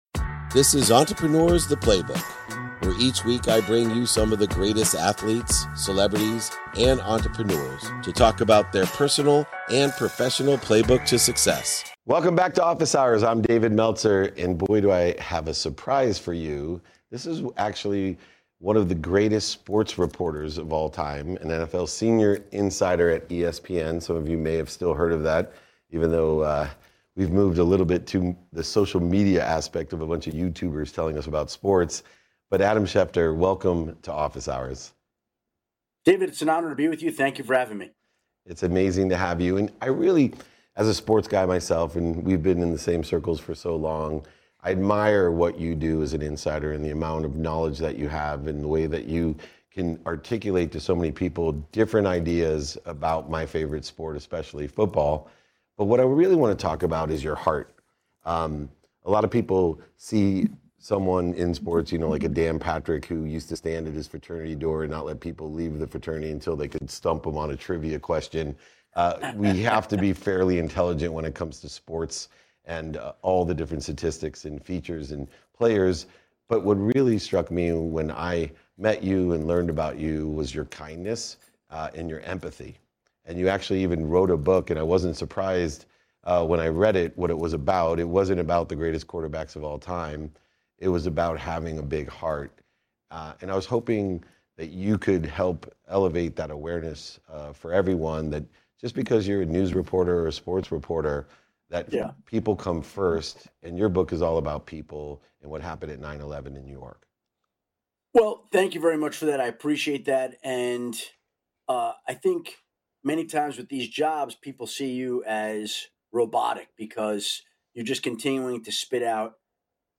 Today's episode dives into a deep conversation with the NFL’s inside man, Adam Schefter. We navigate the challenging world of sports journalism, confronting rejection head-on, and championing empathy and kindness.